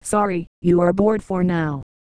Worms speechbanks
boring.wav